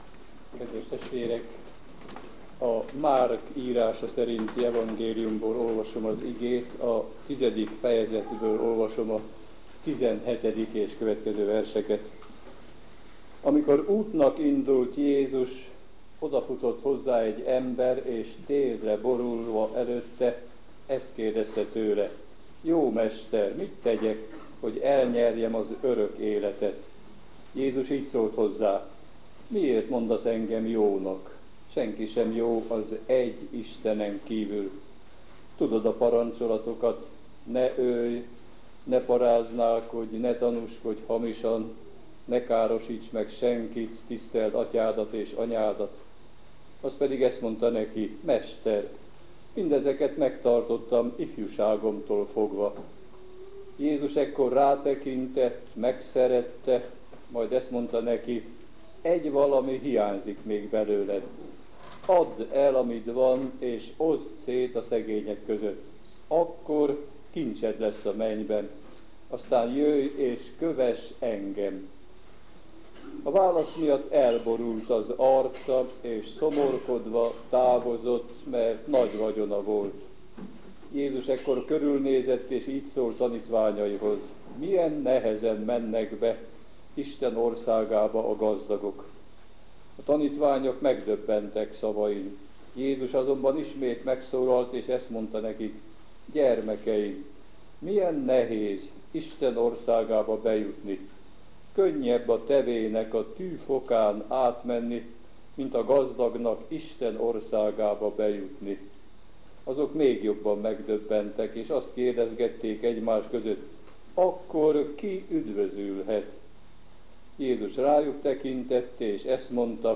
igehirdetései